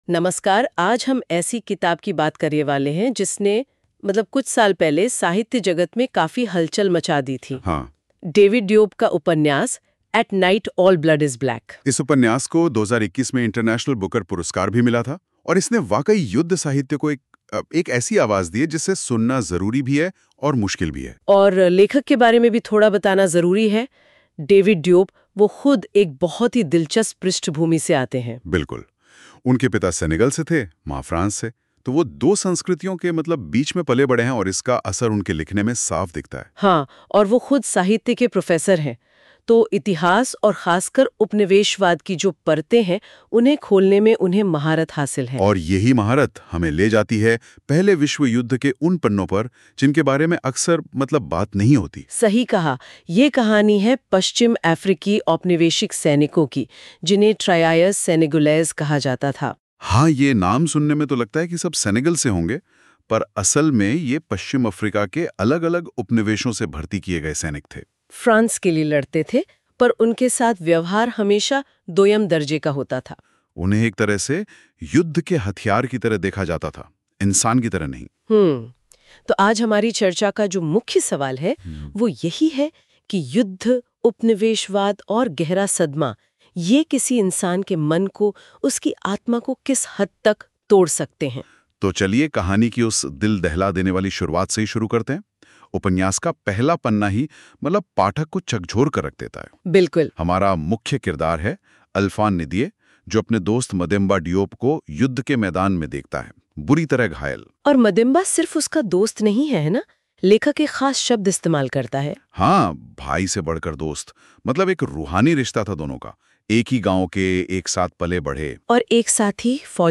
Ekatra audio summary – Hindi